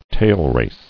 [tail·race]